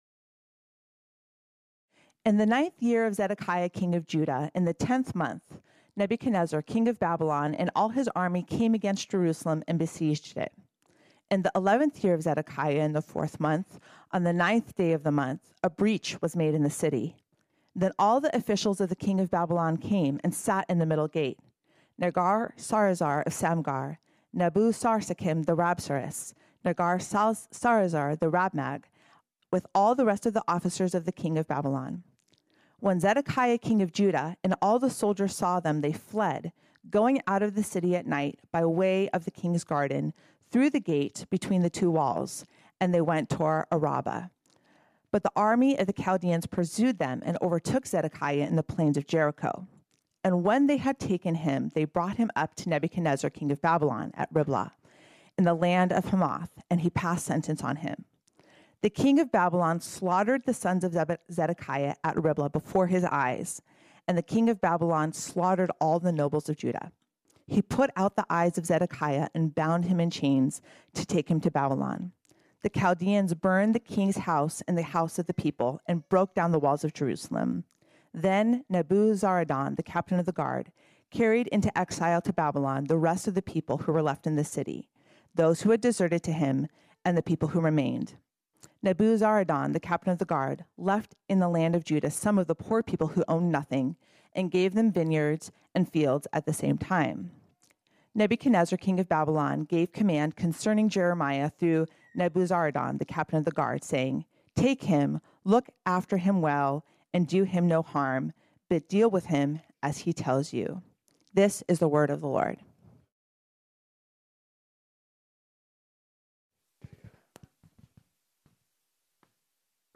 This sermon was originally preached on Sunday, July 31, 2022.